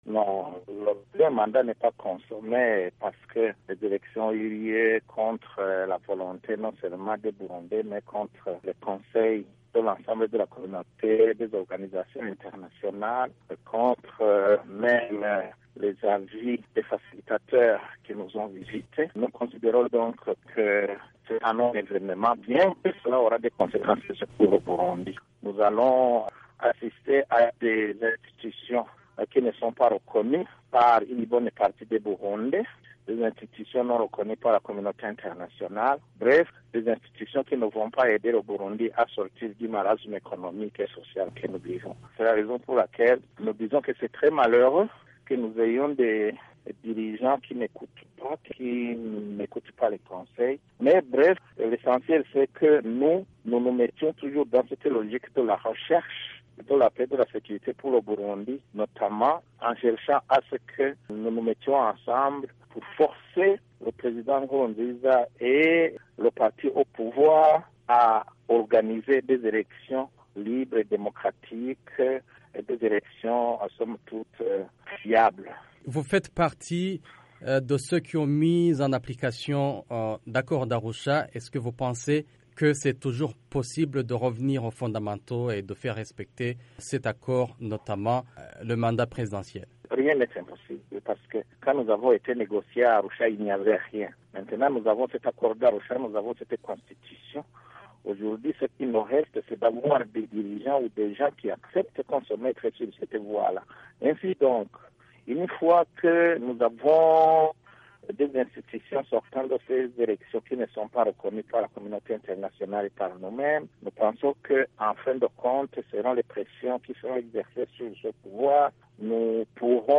Cette victoire est un "non-évènement", selon l’ancien president burundais Domitien Ndayizeye. Joint par VOA Afrique, M.Ndayizeye, candidat à cette élection mais qui s’est retiré en signe de boycott, estime que le combat continue pour faire respecter la démarche démocratique qui respecte l’esprit et la lettre des Accords d’Arusha.